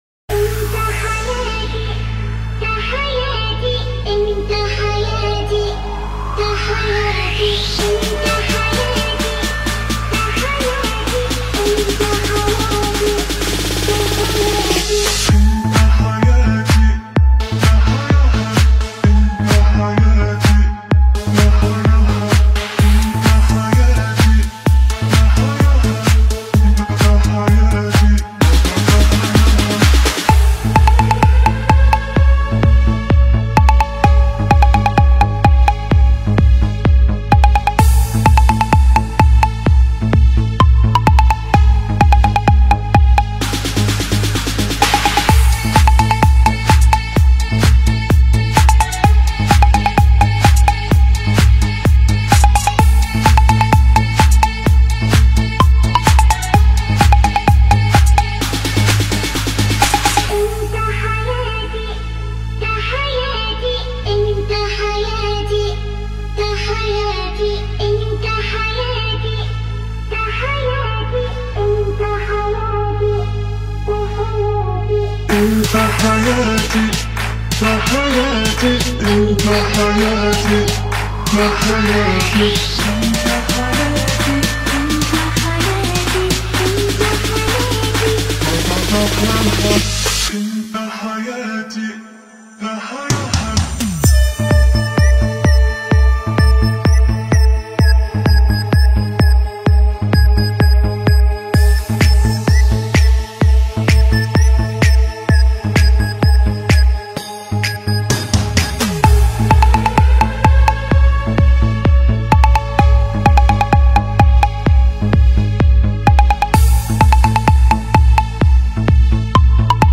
Arabic Remix